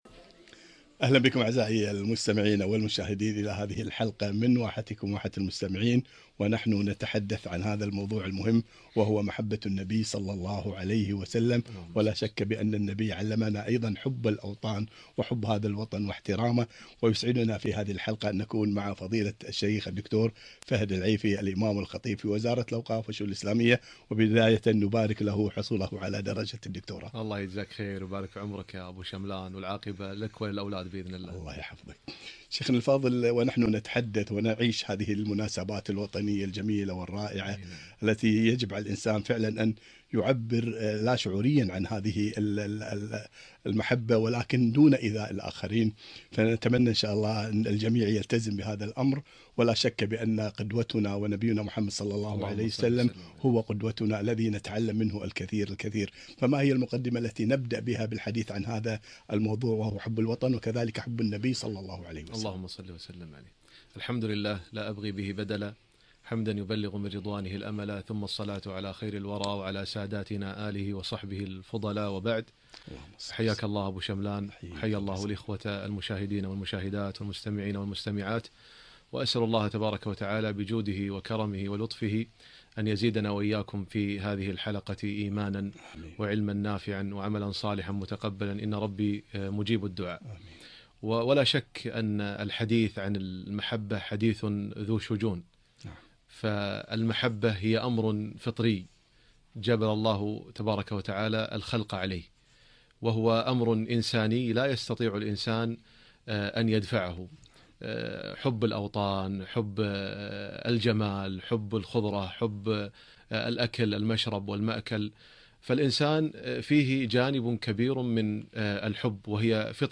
محبة النبي صل الله عليه وسلم - لقاء إذاعي